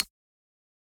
key-press-2.mp3